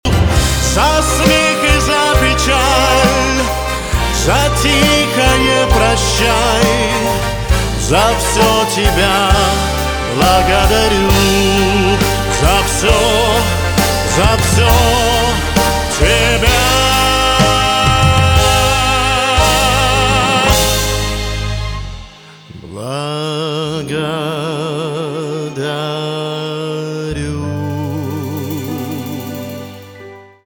поп
красивый мужской голос , пианино , барабаны , чувственные